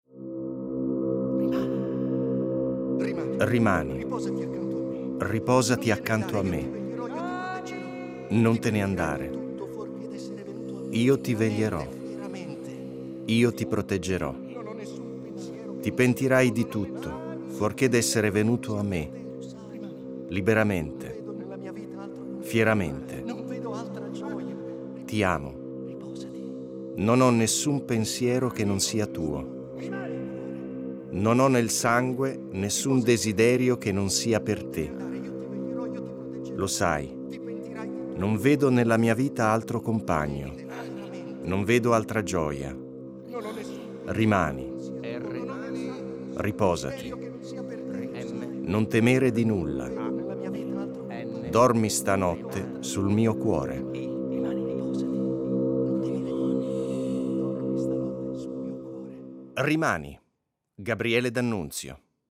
Letta da